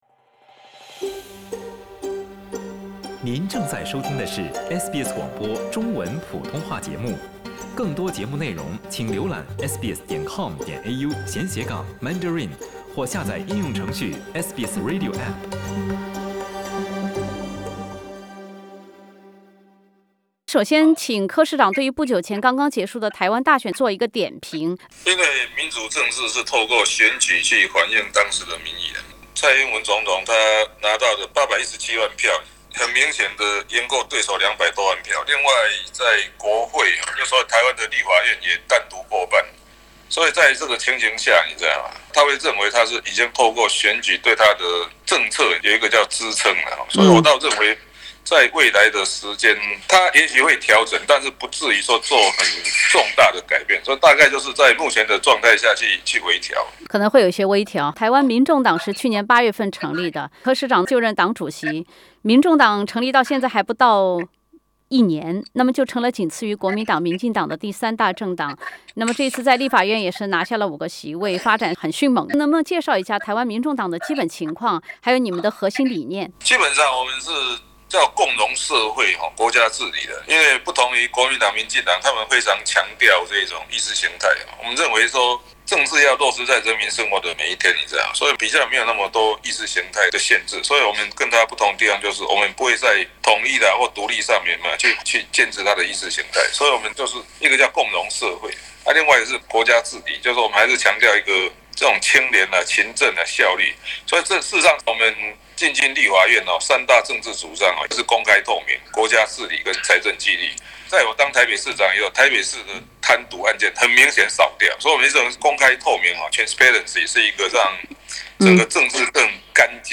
【独家专访】台北市长柯文哲：台湾的防疫体系相当优秀